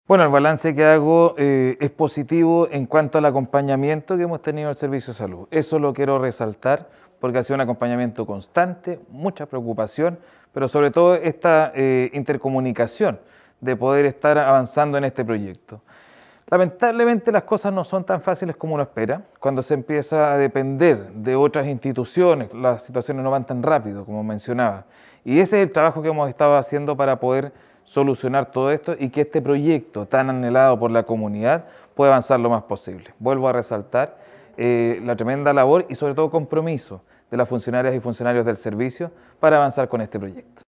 Aldo-Retamal-alcalde-de-Los-Lagos.mp3